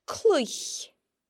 The broad L sound can sound a bit different when combined with another consonant at the beginning of a word, such as in sload (pull) or cluich (play):